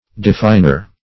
Definer \De*fin"er\, n. One who defines or explains.